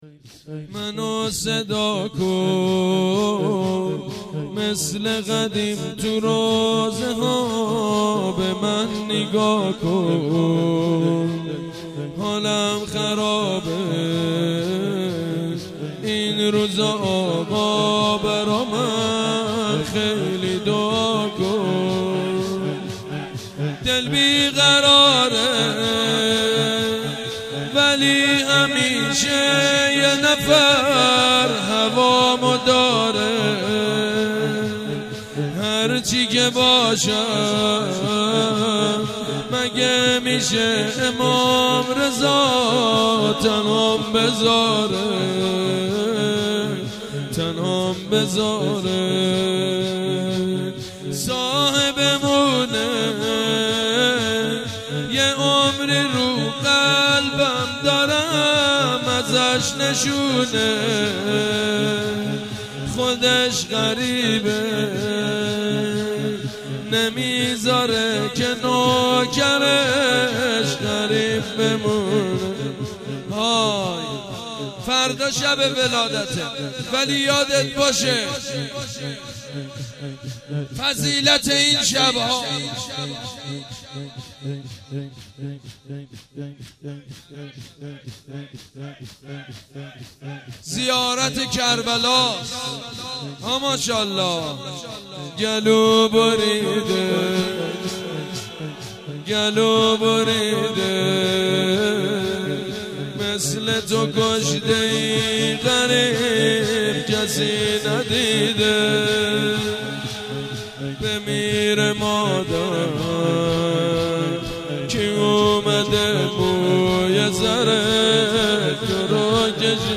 شور: منو صدا کن مثل قدیم تو روضه ها
شور: منو صدا کن مثل قدیم تو روضه ها خطیب: سید مجید بنی فاطمه مدت زمان: 00:07:31